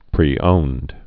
(prē-ōnd)